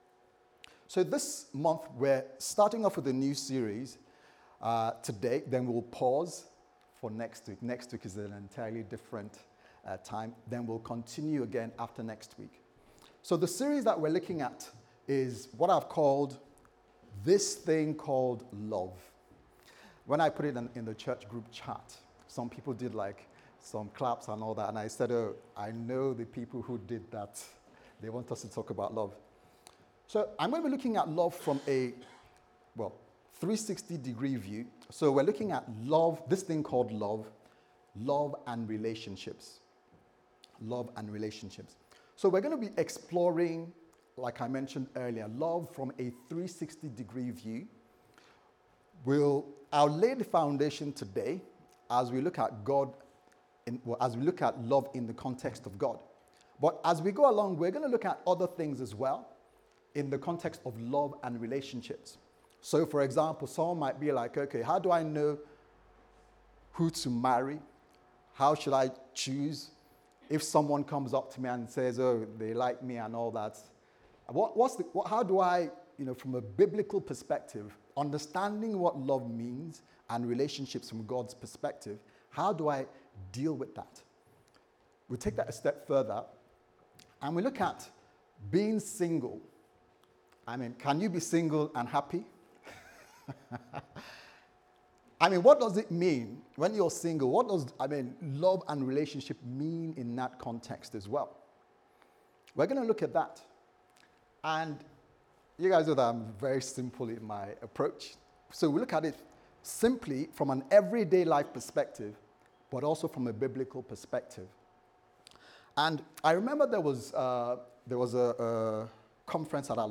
This Thing Called Love Service Type: Sunday Service Sermon « What Am I Concerned About The Future